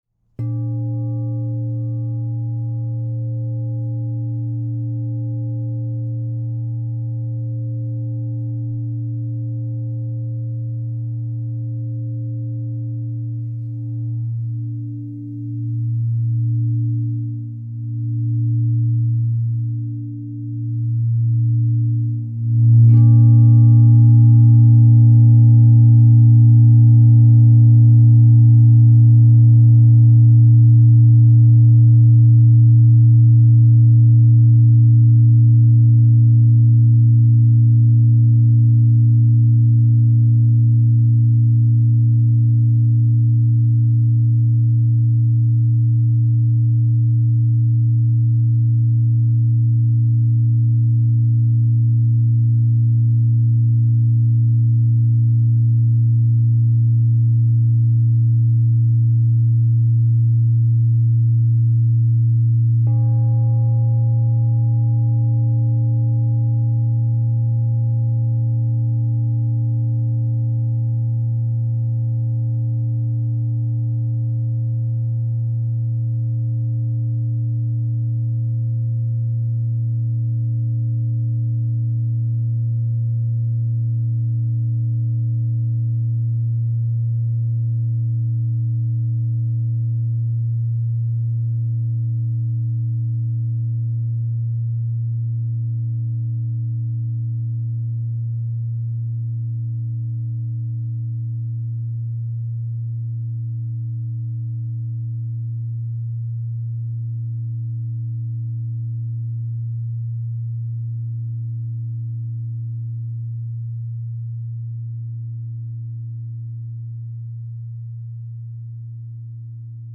Crystal Tones Supergrade True Tone 16 Inch A# Singing Bowl
The Supergrade design ensures exceptional tonal purity, creating an expansive and harmonizing resonance that aligns with the Third Eye Chakra.
The impressive 16-inch size delivers rich, resonant tones that fill any space with luminous frequencies, making this bowl ideal for group sound healing sessions or creating a serene sanctuary.
-10 (True Tone)
432Hz (-), 440Hz (TrueTone)